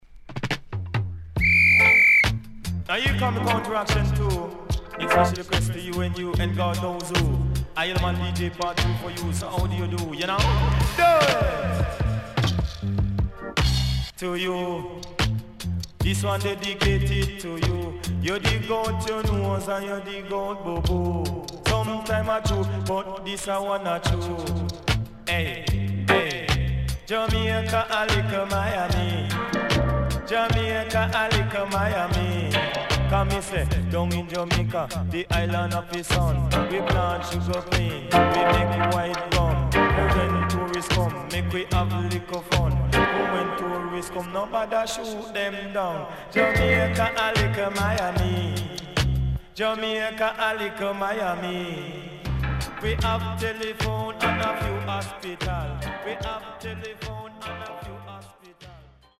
HOME > LP [DANCEHALL]  >  EARLY 80’s
SIDE B:所々チリノイズがあり、少しプチノイズ入ります。